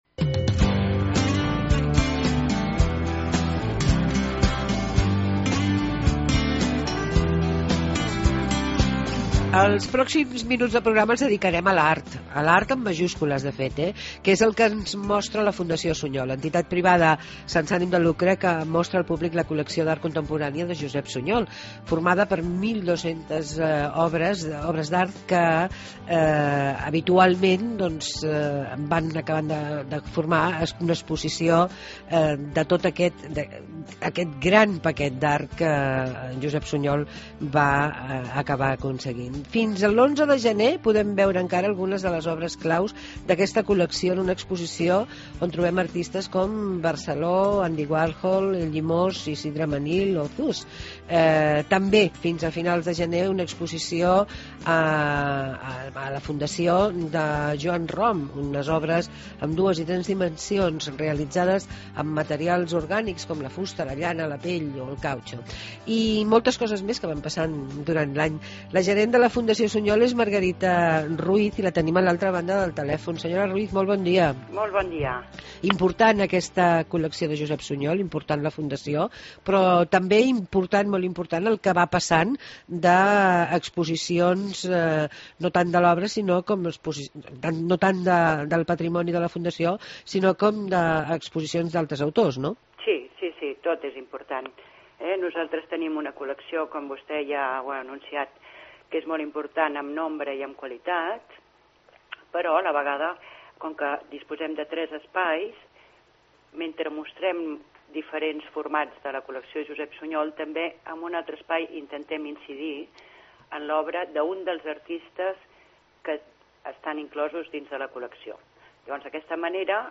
AUDIO: Proposta ACT: Visitar la Fundació Suñol. Entrevista